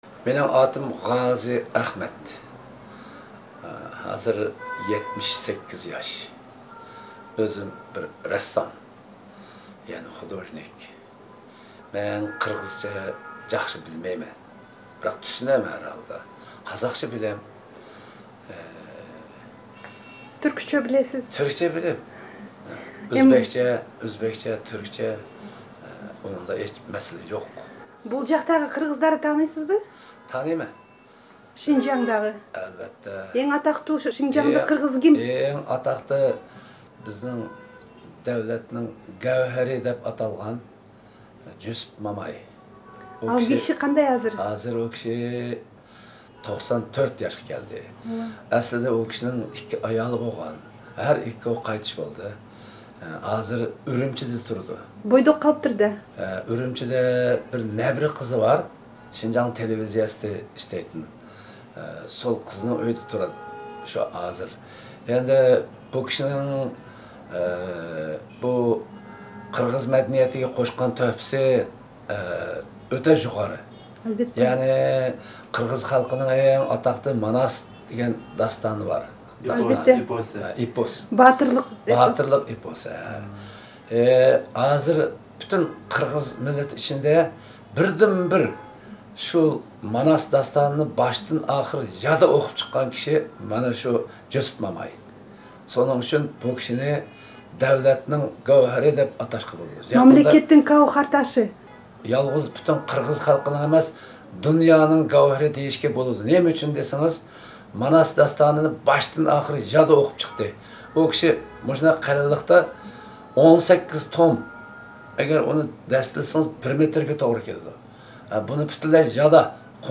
Interview recorded in Turfan in May 2011